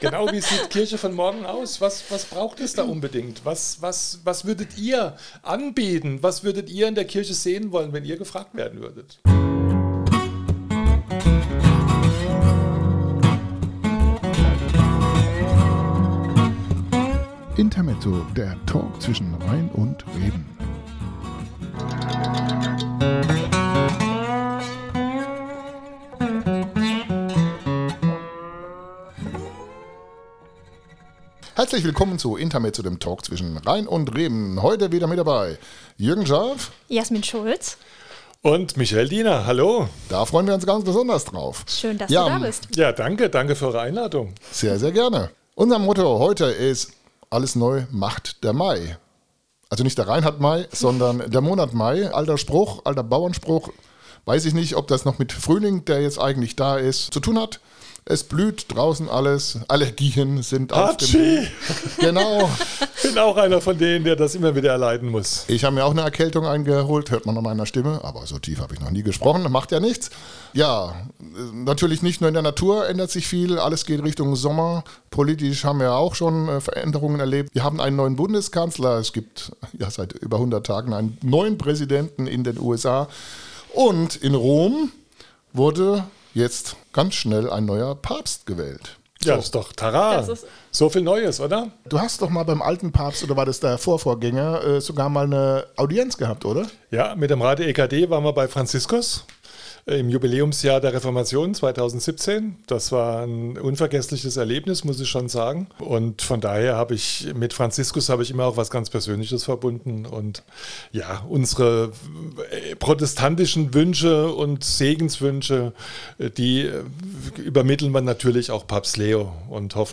Intermezzo #12 | Alles neu macht der Mai ... ~ INTERMEZZO - der Talk zwischen Rhein & Reben Podcast